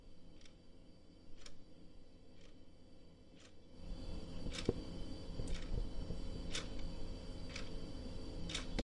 钟表
描述：一个模拟时钟每秒钟滴答，记录在一个有一些回声的大房间里。